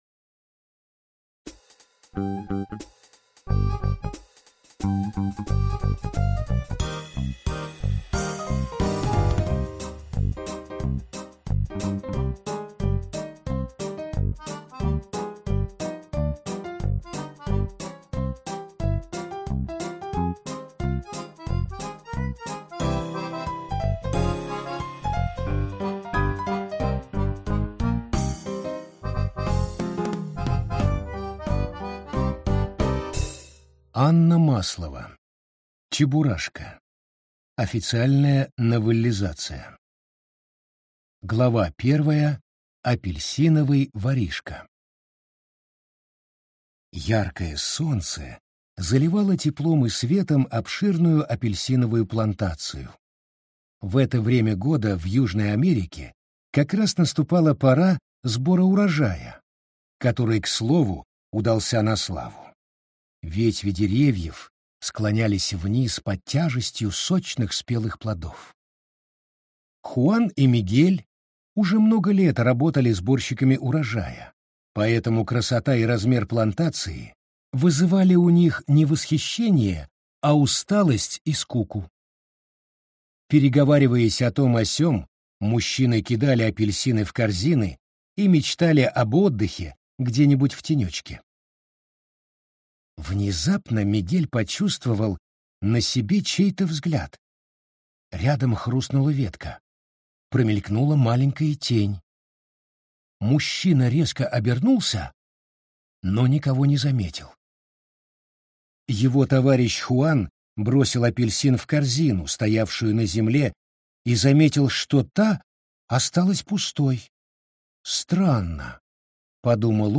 Аудиокнига Чебурашка. Официальная новеллизация | Библиотека аудиокниг